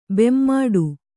♪ bemmāḍu